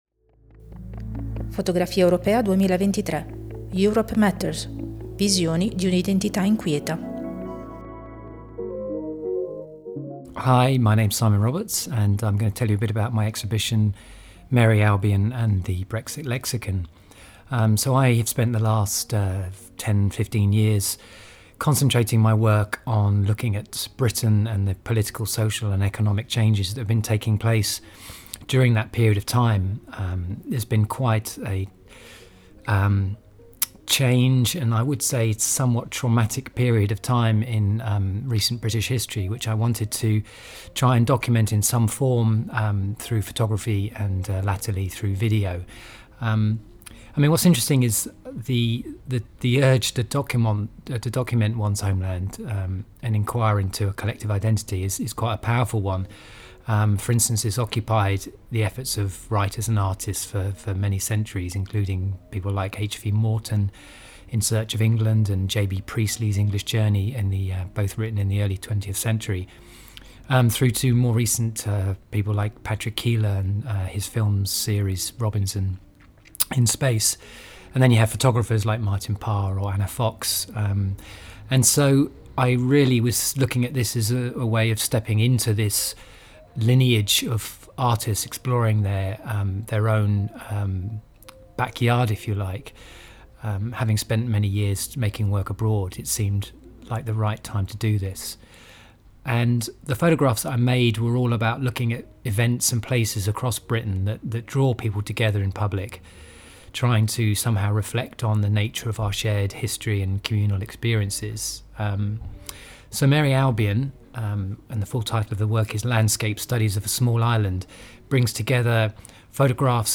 Listen to the words of the artist